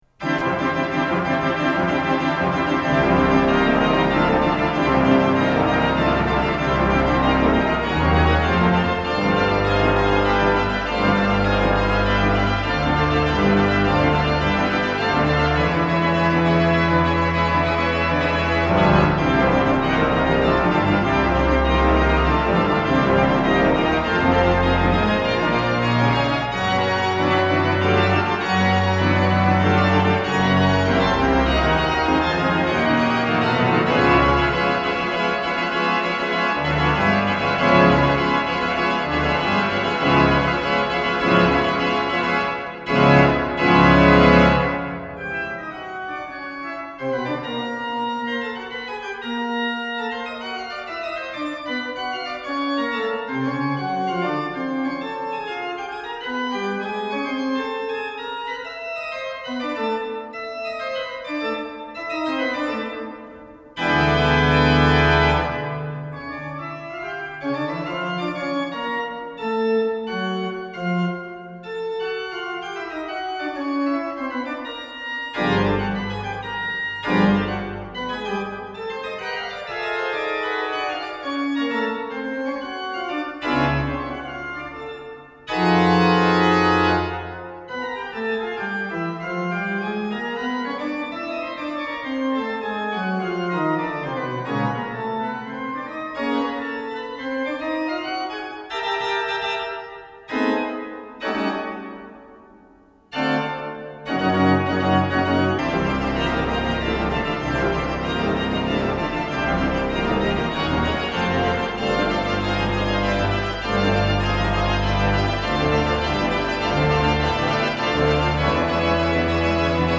Orgel des Wiener Funkhauses